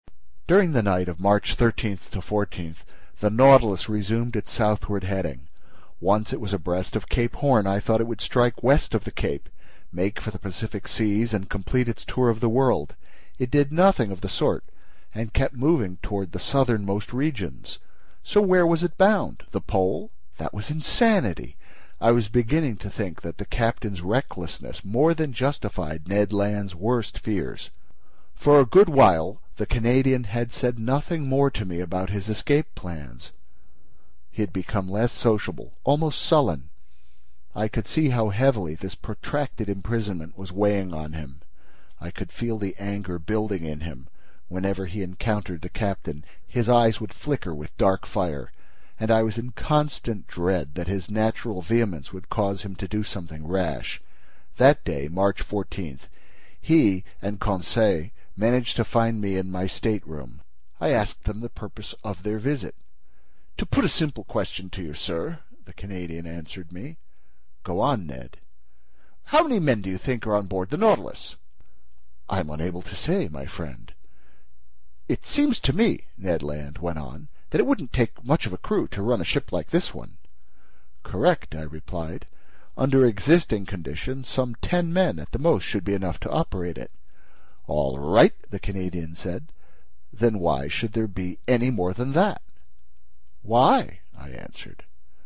英语听书《海底两万里》第420期 第26章 大头鲸和长须鲸(1) 听力文件下载—在线英语听力室